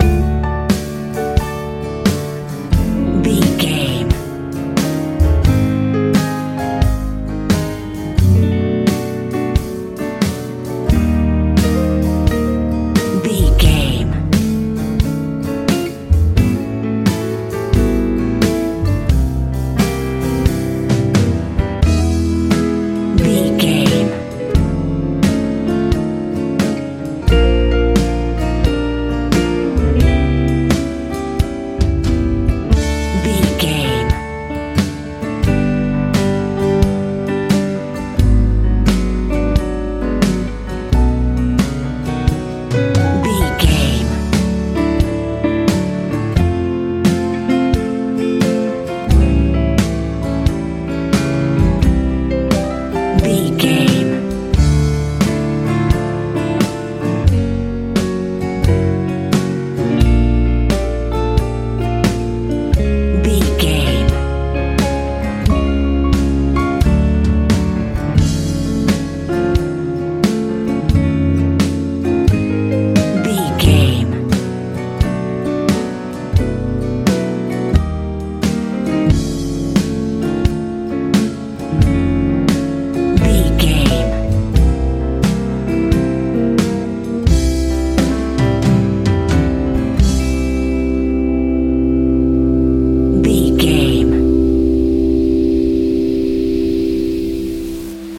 lite pop feel
Ionian/Major
D
calm
joyful
light
piano
acoustic guitar
electric guitar
bass guitar
soothing
soft
smooth
relaxed